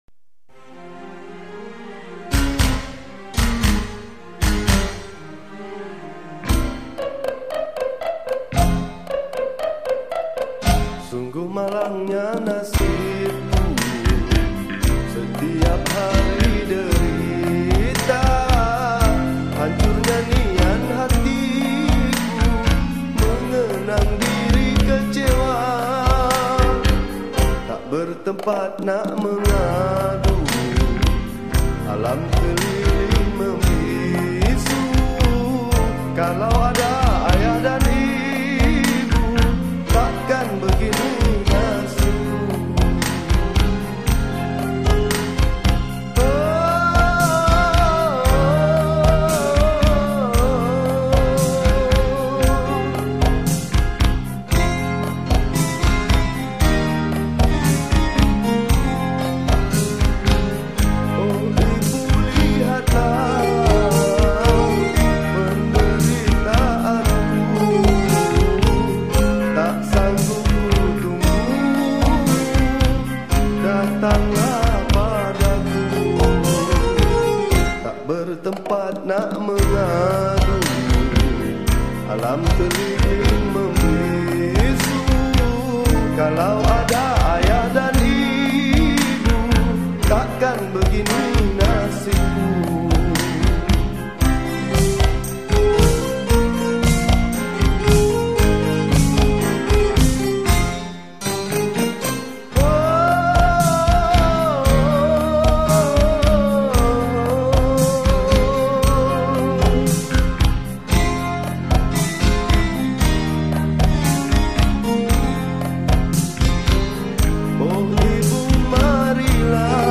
Chords : Dm
Malay Song